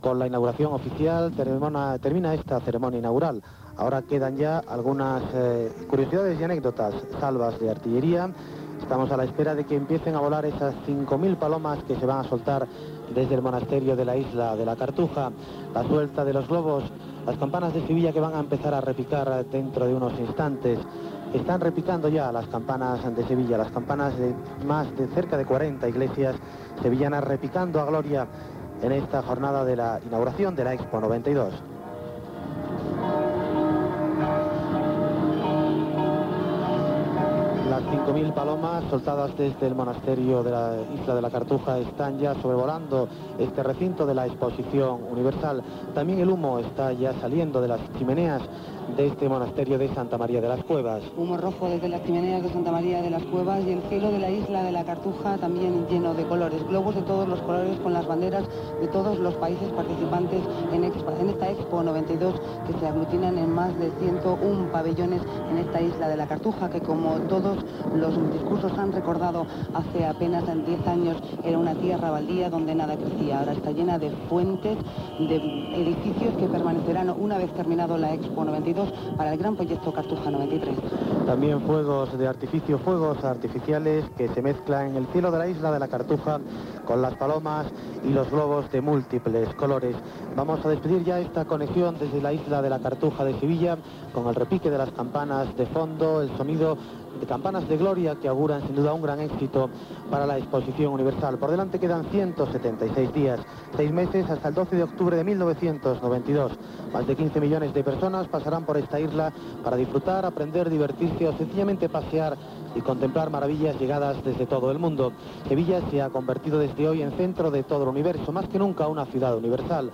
Programa especial de la inauguració de l'Expo de Sevilla 1992. Part final de la transmissió amb el repic de campanes, l'alliberament de coloms i focs artificails.
Informatiu